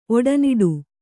♪ oḍaniḍu